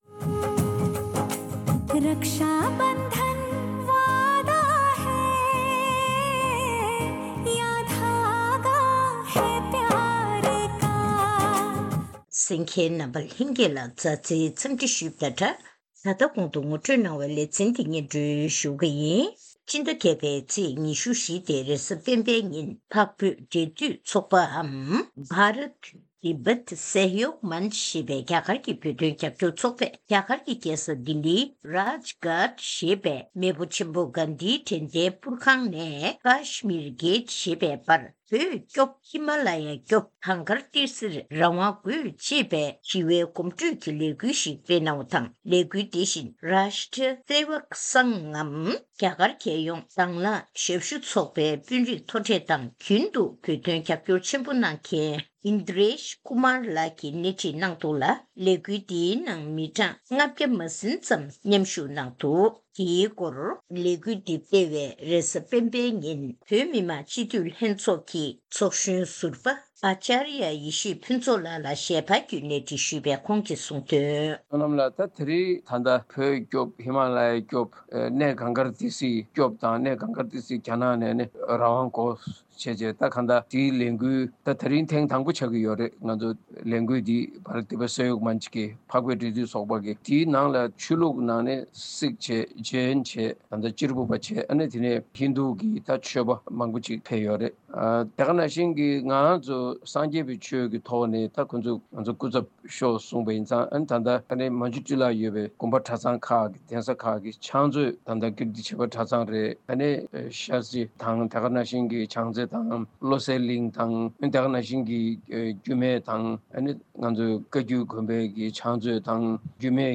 ཐེངས་འདིའི་གནས་འདྲིའི་ལེ་ཚན་ནང་རྒྱ་གར་གྱི་རྒྱལ་ས་ལྡི་ལིའི་ནང་བོད་སྐྱོབ་ཧི་མ་ལ་ཡ་སྐྱོབ་གངས་ཏི་སིར་རང་དབང་དགོས་ཅེས་པའི་གོམ་བགྲོད་ལས་འགུལ་སྤེལ་བ་དང་།